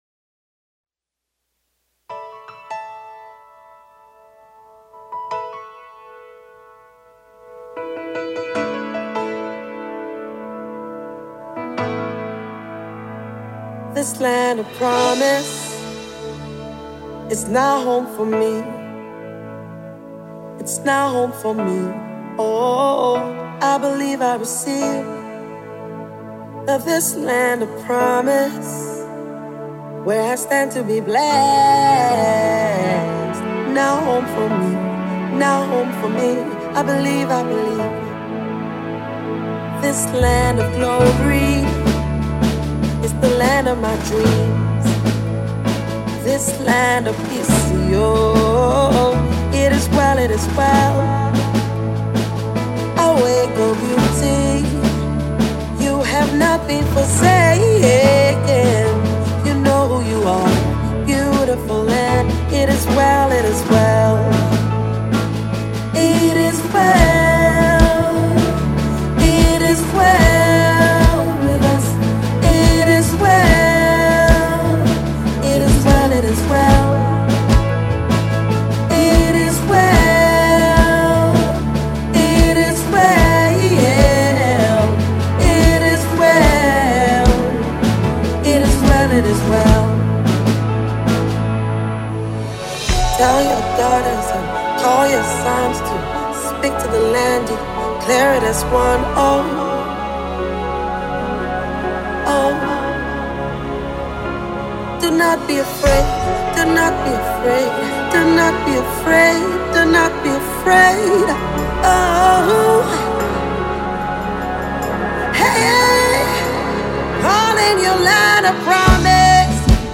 background vocals
drums
guitar
piano
keys